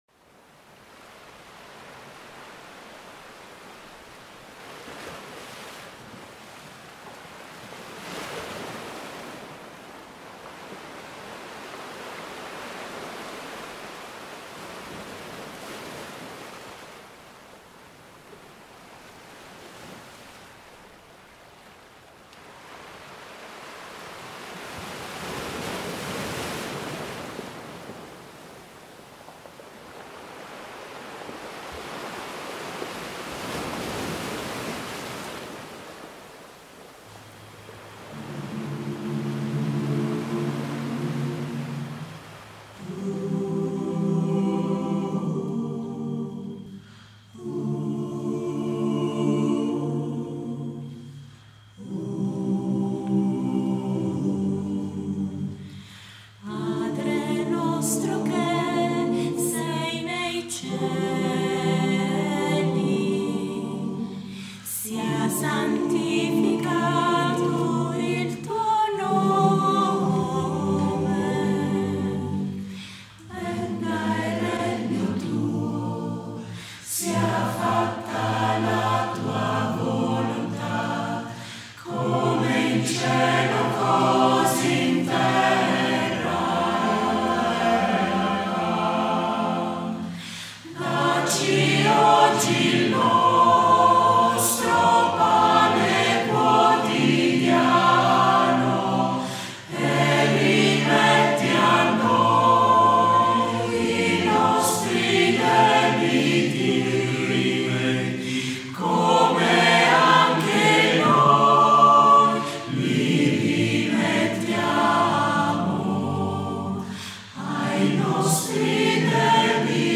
- Œuvre pour choeur mixte à 7 voix à capella (SSAATBB)